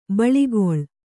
♪ baḷigoḷ